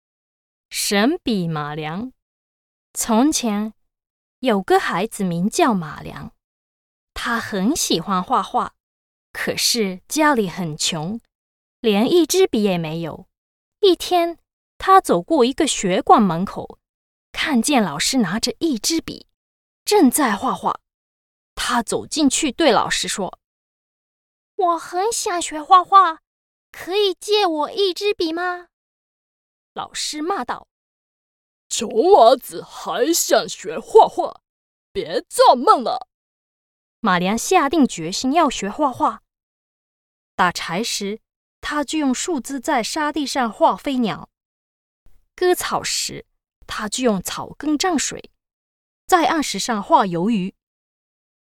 Native speaker Female 20-30 lat
Demo w języku chińskim